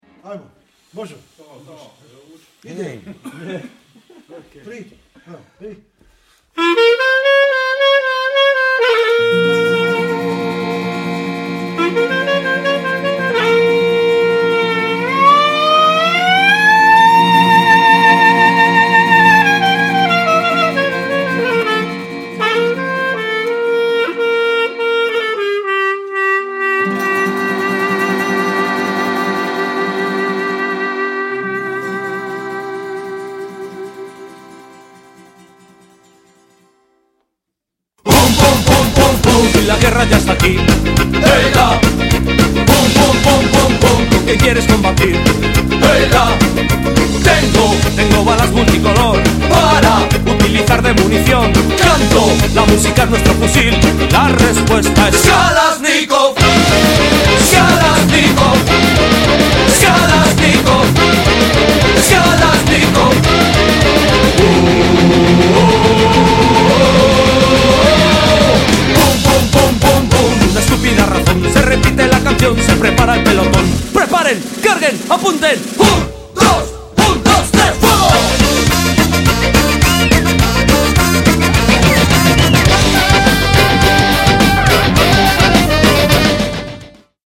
そのサウンドはストレートで全開、CDからも充分に伝わるパワーに熱くなることは必至！
そのエキゾチックなメロディが東欧ジプシー音楽風味を色漬け、新鮮さをプラスしている。